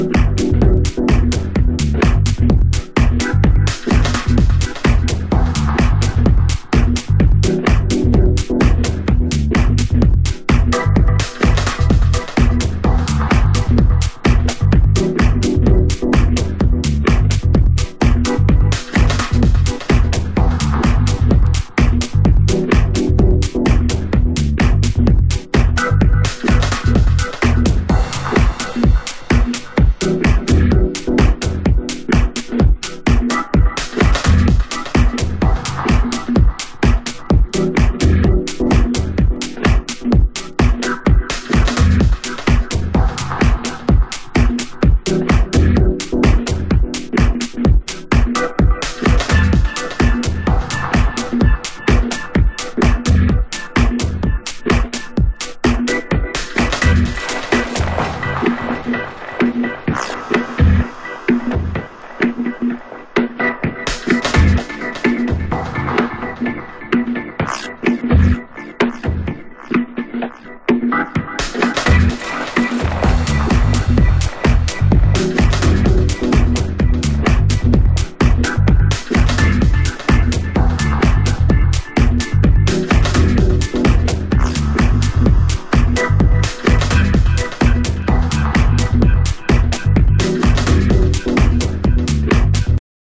盤質：傷によるチリパチノイズ有